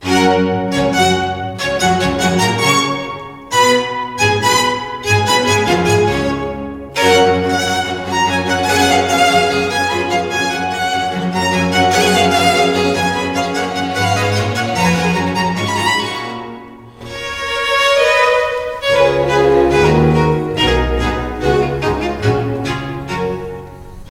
Klasyczny